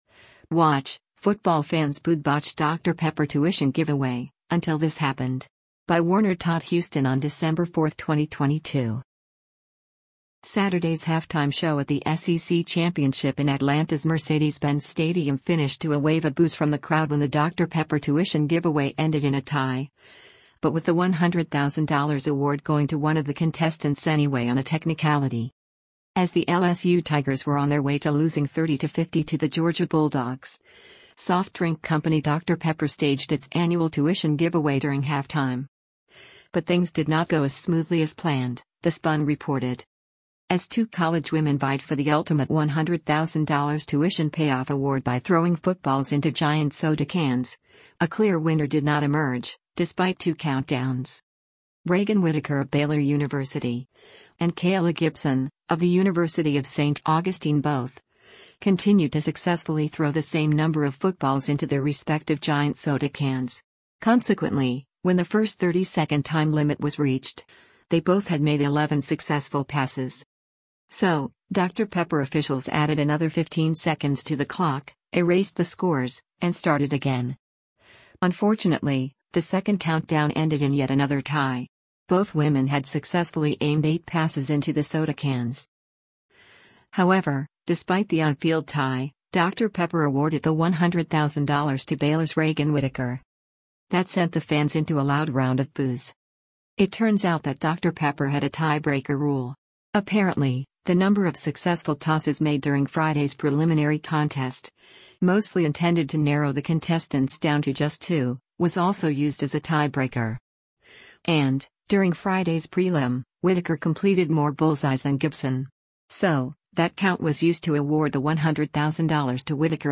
That sent the fans into a loud round of boos.